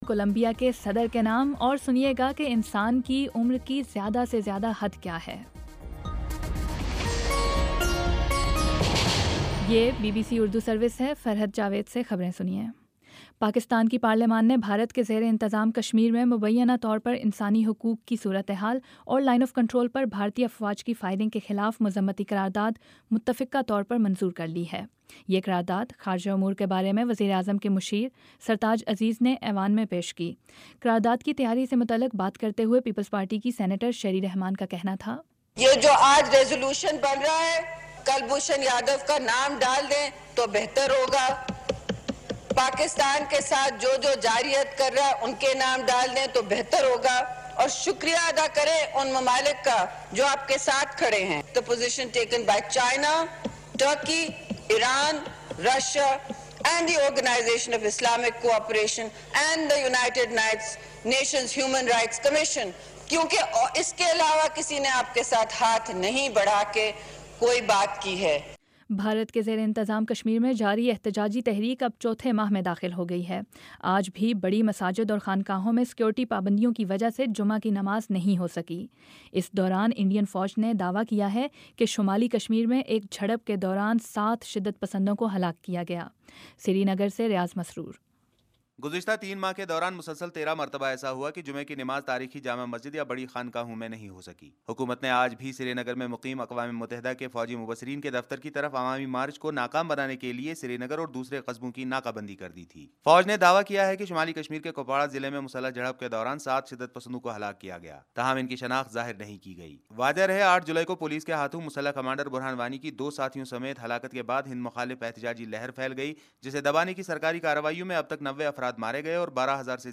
اکتوبر 07 : شام چھ بجے کا نیوز بُلیٹن